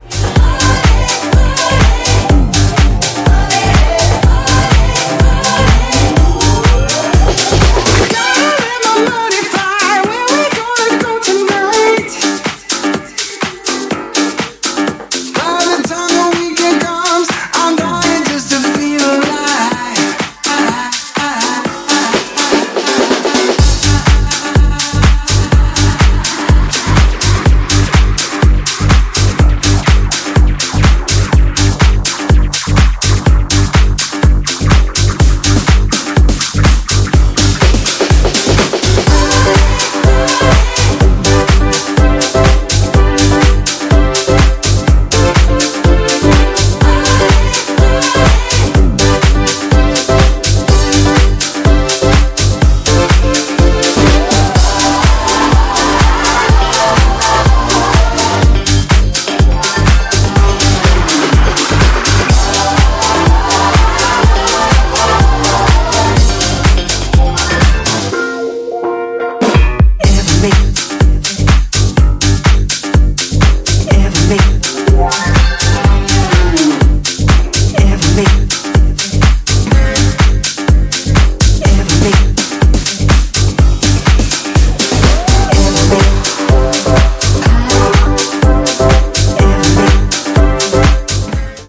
ジャンル(スタイル) SOULFUL HOUSE / NU DISCO